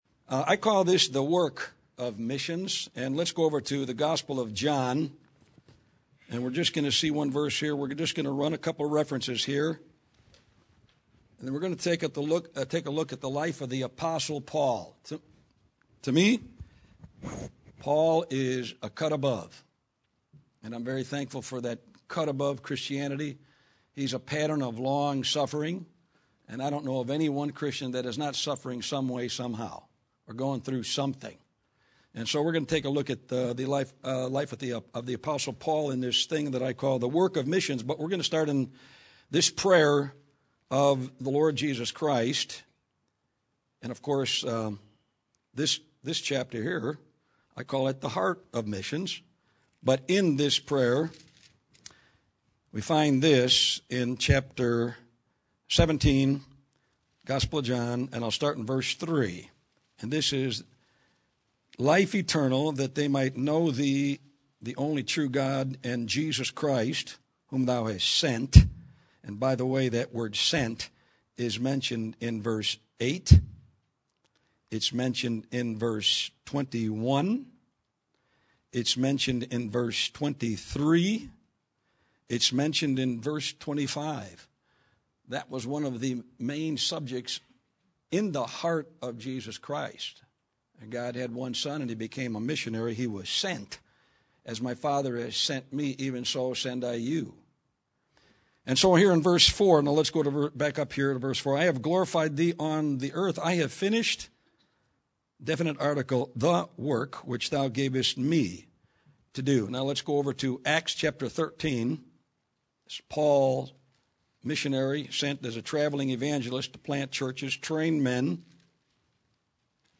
This is a sermon on the work of missions.
This Sunday School sermon is about the work of missions.